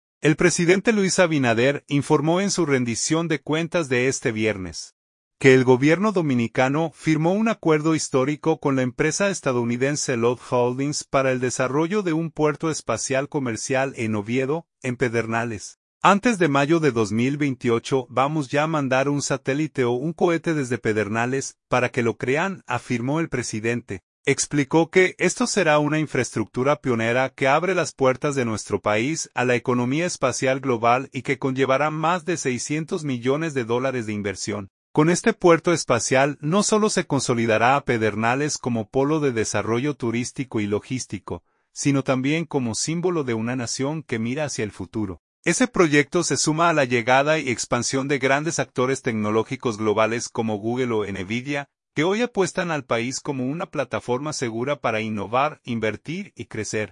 El presidente Luis Abinader, informó en su rendición de cuentas de este viernes, que el Gobierno dominicano firmó un acuerdo histórico con la empresa estadounidense LOD Holdings para el desarrollo de un puerto espacial comercial en Oviedo, en Pedernales.